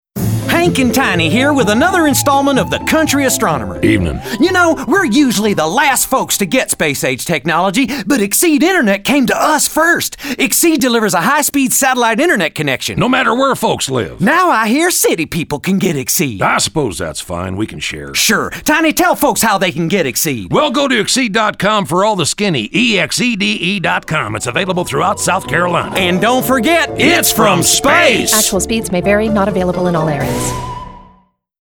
We did several of these spots, featuring two country gentlemen who dabbled in astronomy but talked about internet service from space.